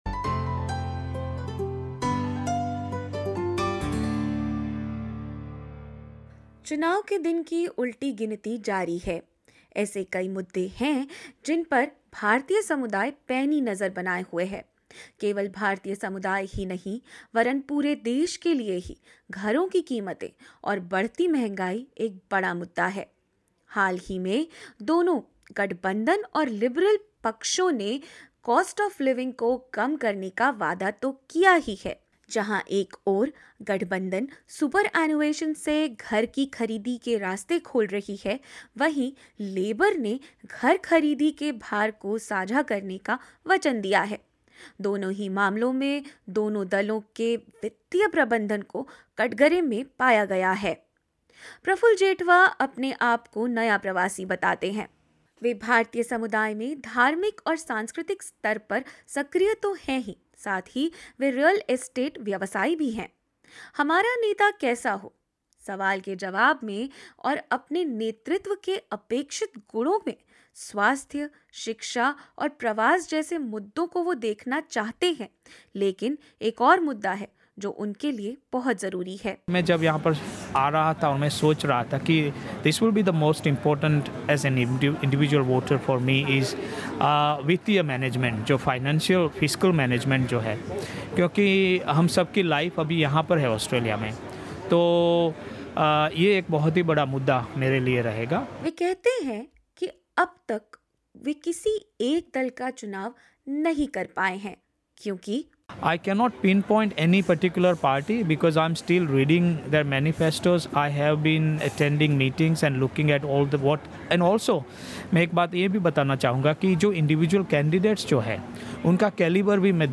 SBS Election Exchange held in Sydney recently was attended by members of the Indian Australian community who spoke about their expectations for the incoming federal government. This second episode of a five-part 'Humara Neta Kaisa Ho' series explores why housing affordability is a key issue for the community during this election.